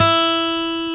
Index of /90_sSampleCDs/Roland L-CD701/GTR_Steel String/GTR_18 String
GTR 6 STR E5.wav